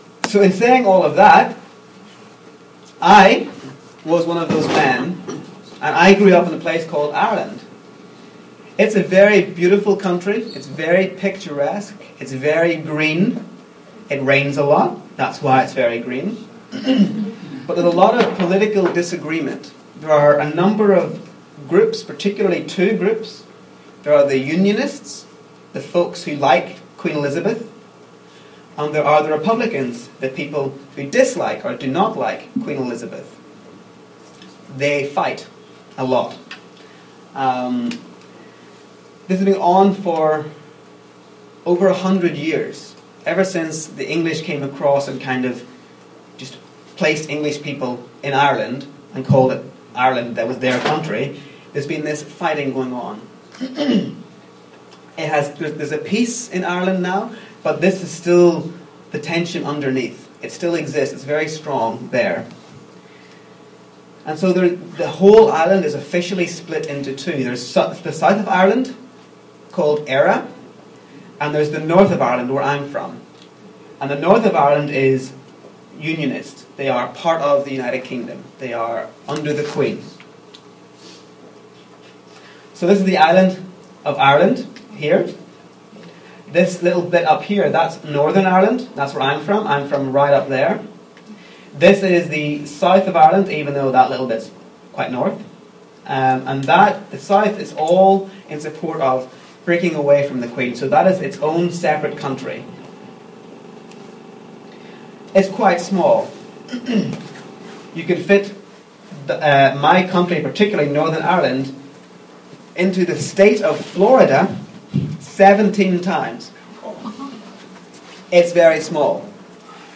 特别聚会